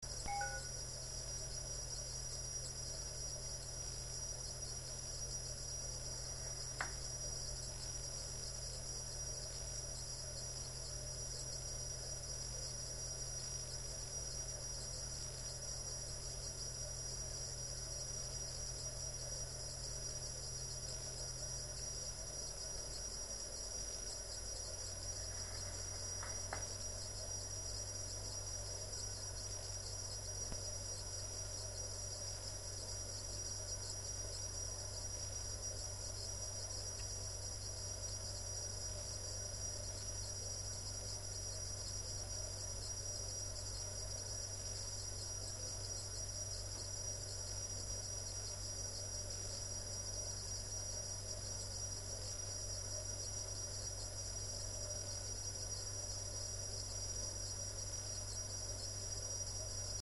Un bruit d'écoulement et un bruit de roulement moteur (mettre le volume haut)
On entend bien un bruit strident pouvant être causé par un rétrécissement de tuyau localement, dans le split, mais sans doute pas ailleurs.
bruit-clim.mp3